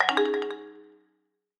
En Güzel 2026 Bildirim Sesleri İndir - Dijital Eşik